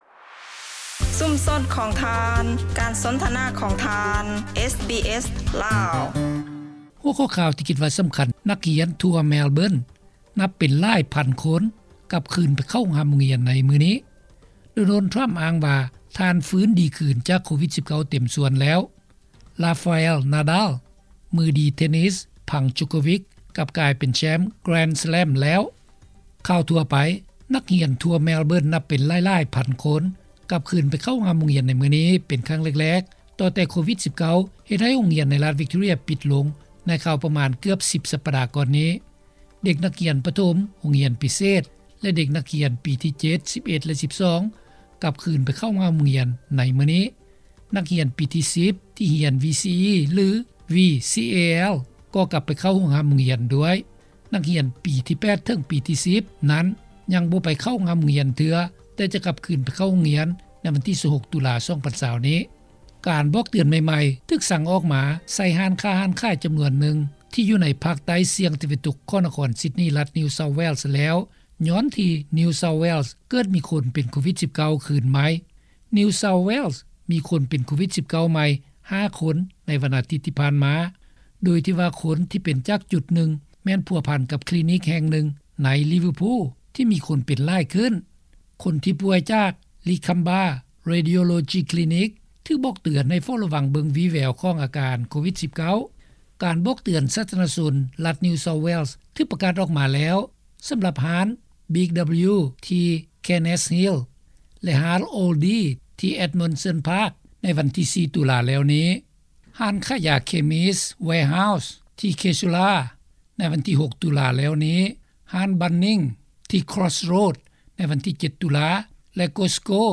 ຂ່າວຄາວ 12-10-28